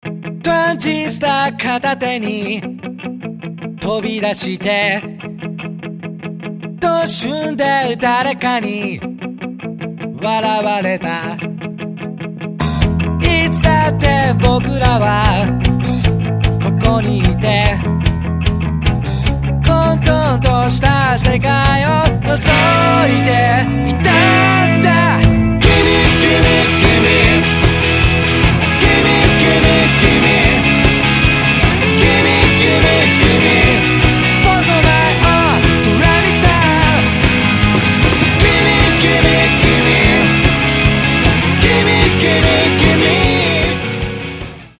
どこまでもエヴァー・グリーンなパワー・ポップ！！！！